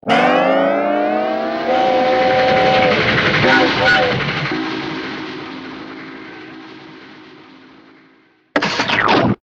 TL_train.ogg